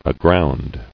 [a·ground]